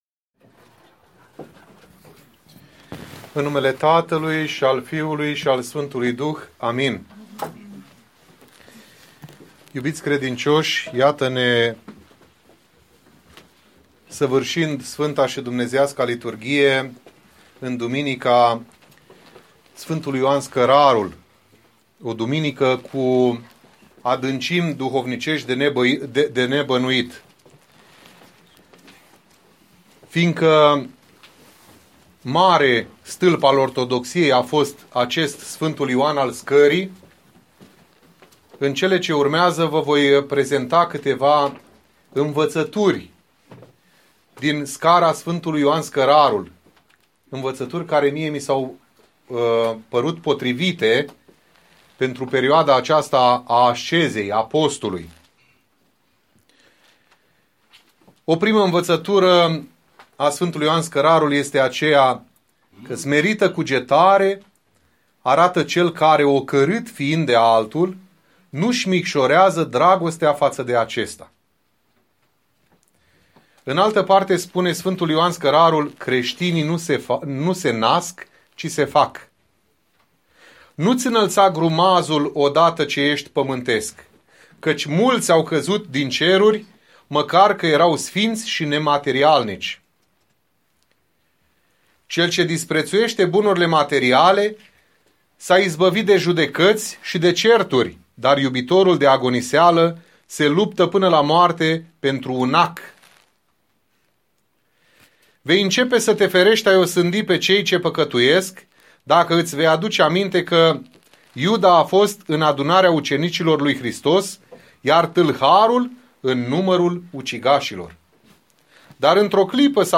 Predica
la Duminica a IV-a din Post